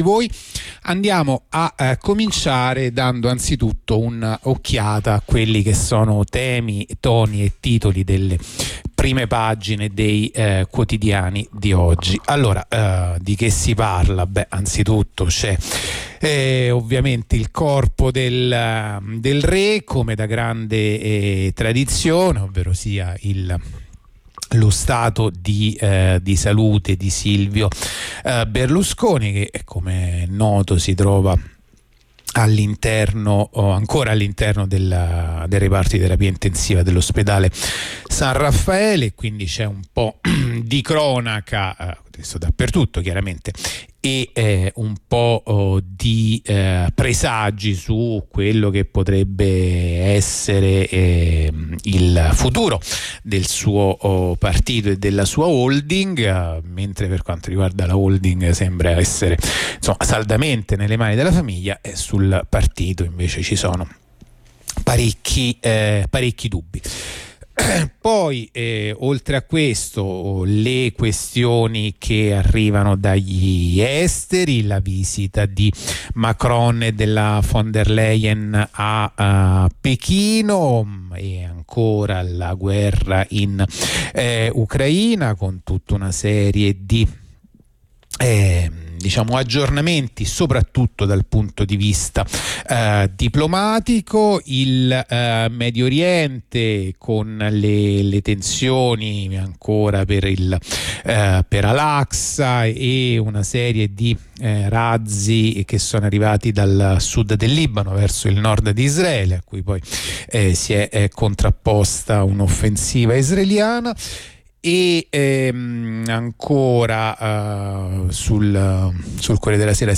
La rassegna stampa di radio onda rossa andata in onda venerdì 7 aprile 2023